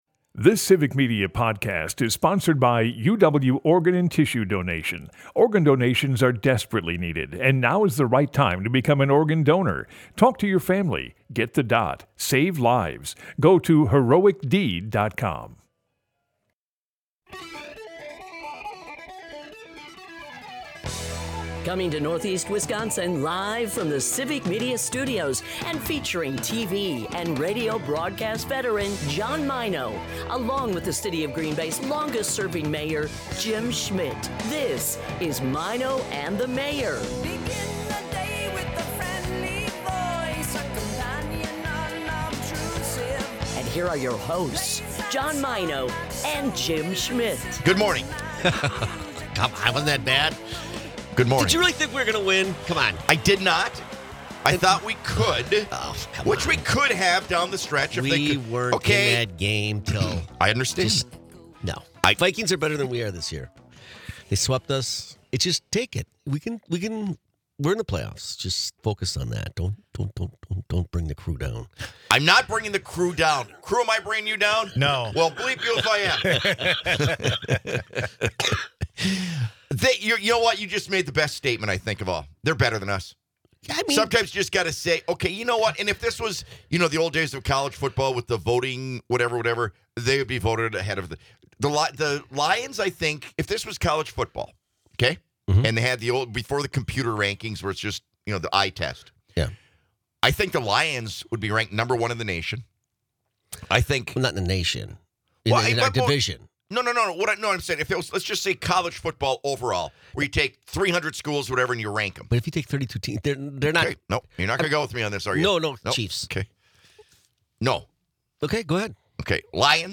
After another Packer loss, the guys talk about which teams are at the top of the NFL.